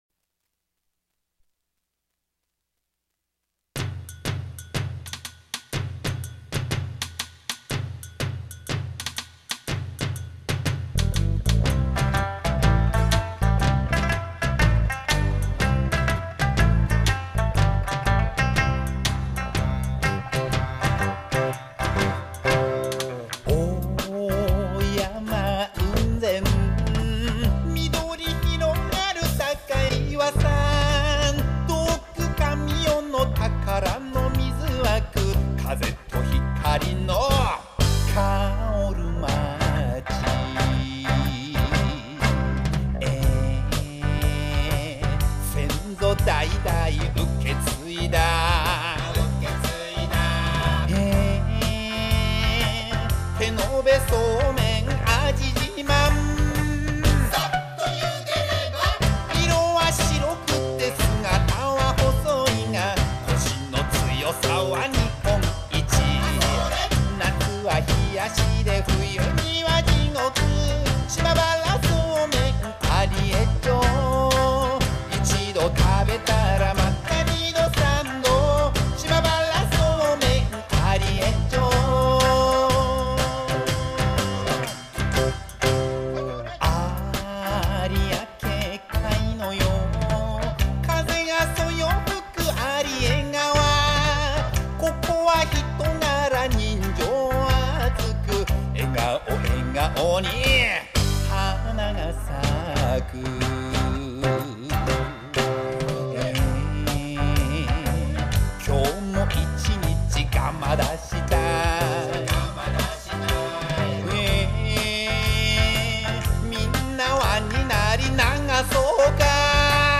ペルー民謡
亜米利加民謡